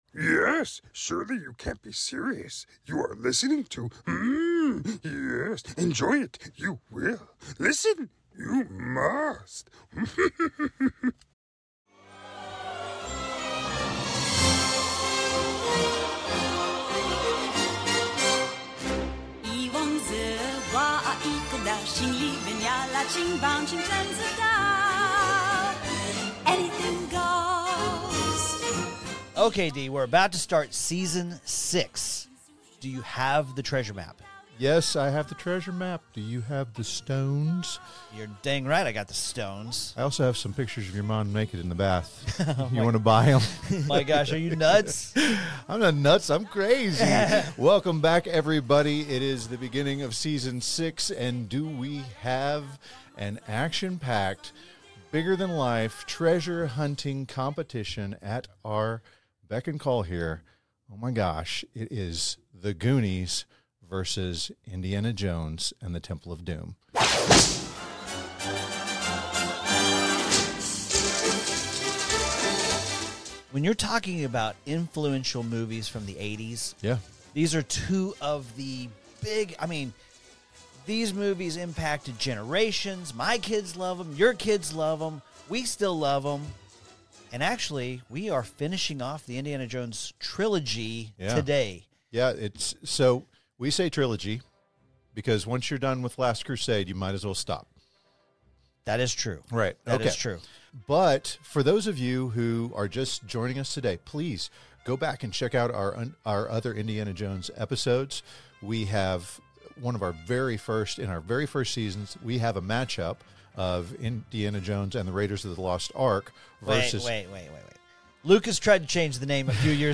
With their signature wit and deep love for pop culture, this lively group provides their own audio commentary as they revisit the explosive action, iconic one-liners, and holiday spirit that make Die Hard a beloved December tradition.